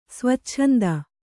♪ svacchanda